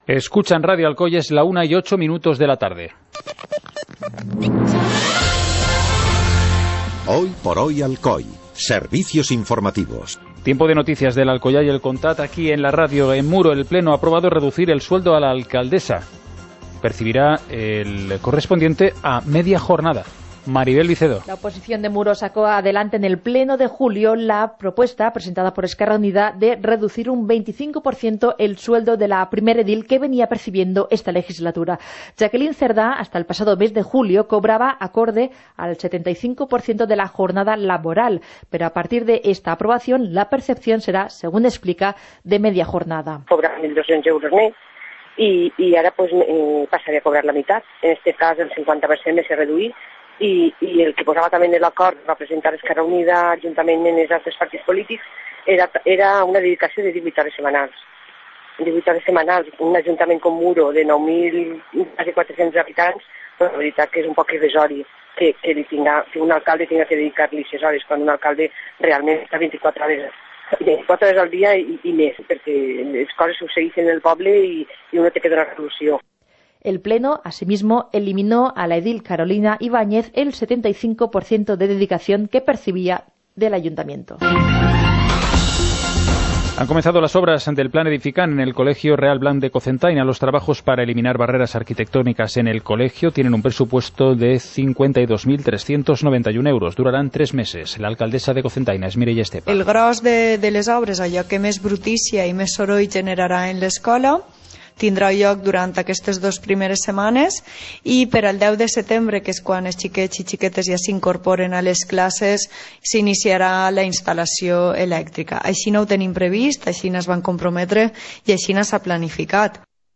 Informativo comarcal - miércoles, 29 de agosto de 2018